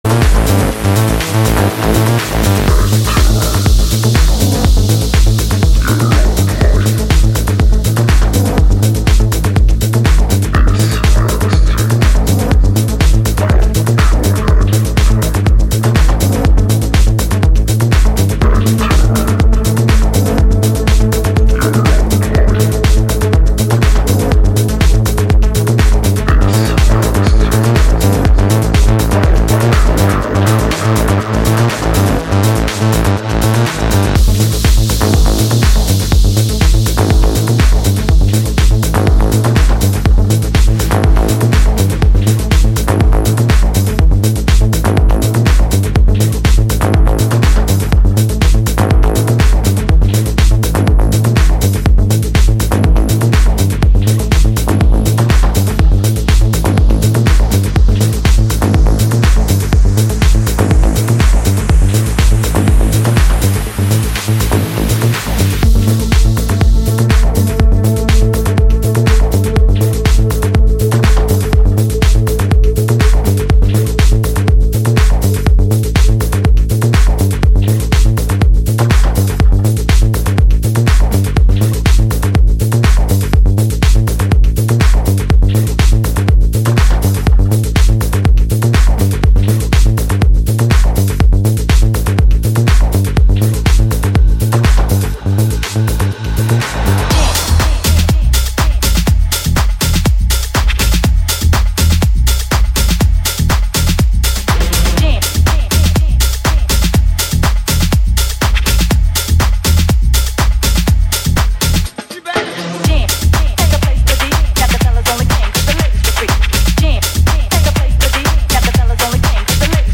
Minimal_Tech_-_10_07_17_-_02-54-11